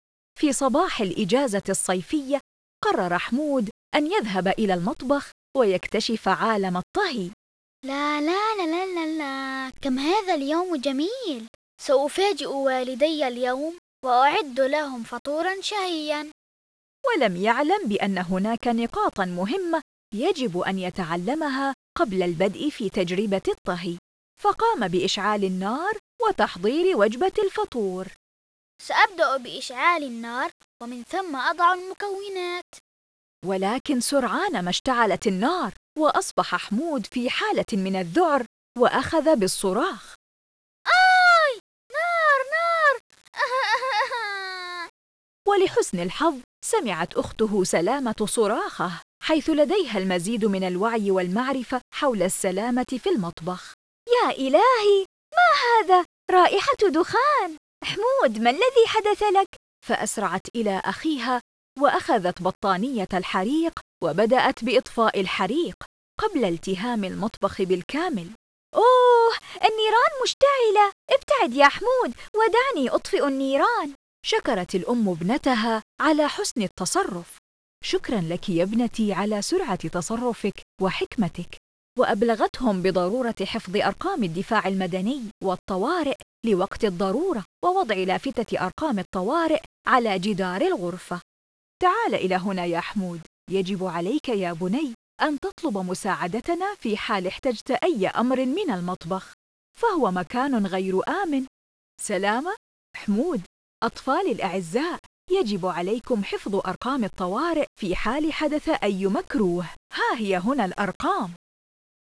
Children's Audio Stories Available in All Arabic مخاطر قنوات الصرف الصحي مخاطر التحميل الكهربائي مخاطر الانزلاق مخاطر إشعال النار الحلقة 4 - مخاطر تسلق الألعاب المرتفعة مخاطر في المسبح مخاطر الألعاب النارية